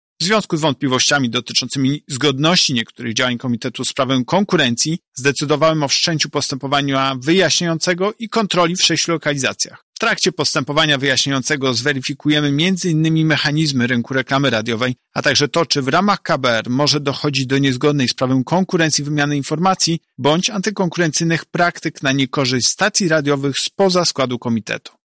-mówi prezes UOKIKu Tomasz Chróstny